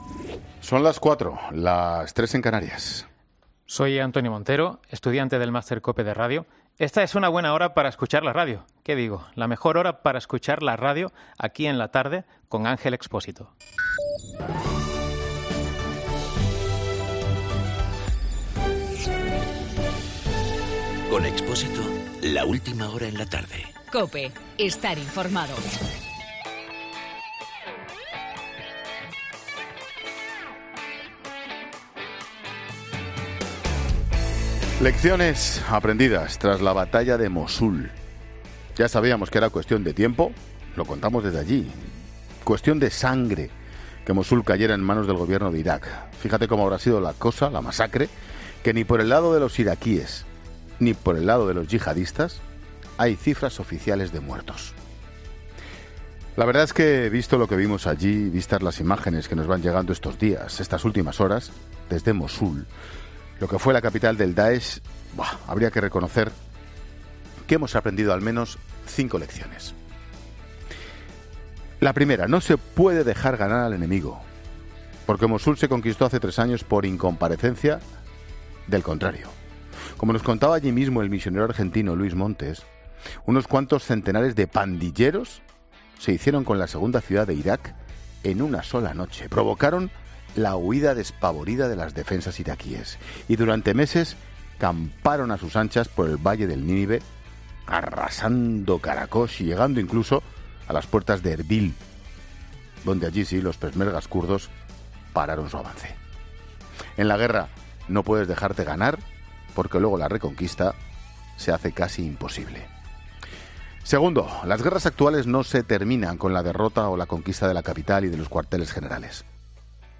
AUDIO: Monólogo de Ángel Expósito de las 16h con las cinco lecciones aprendidas en Mosul.